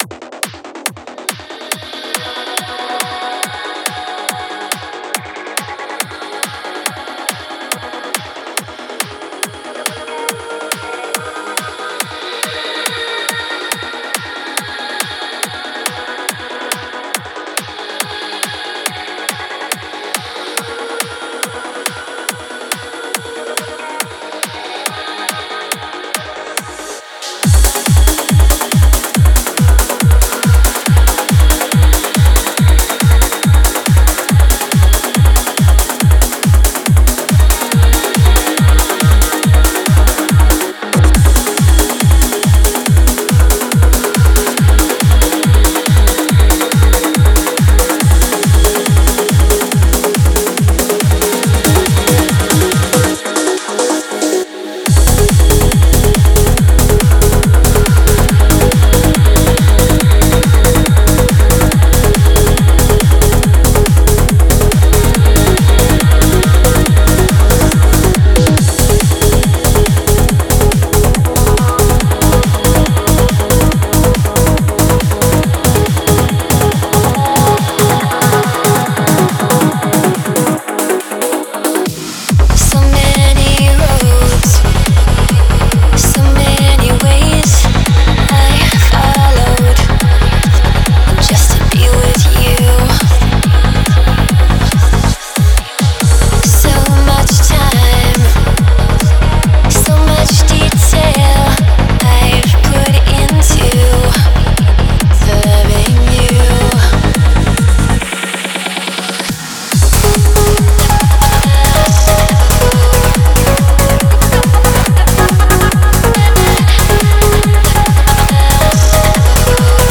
Стиль: Vocal Trance / Uplifting Trance / Progressive Trance